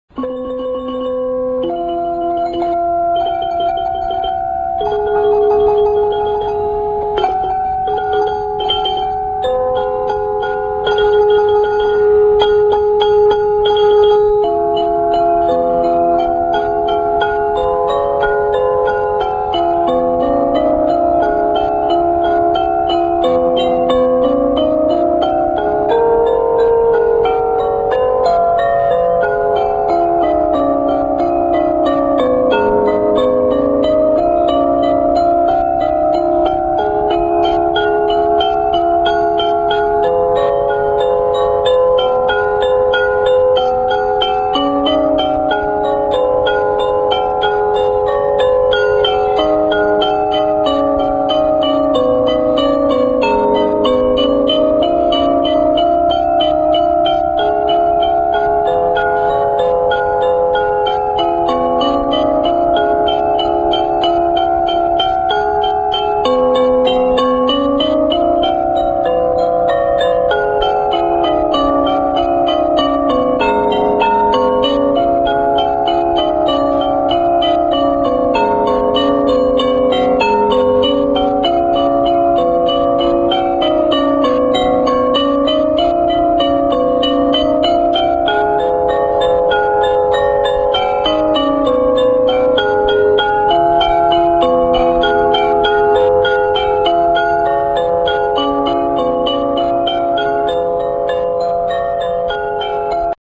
Ranad Ek Lek
This instrument has metal keys and actually ought to be listed in the section on the metal instruments, but, because it is built and played in a similar manner to the wooden ranad, it is discussed in this section.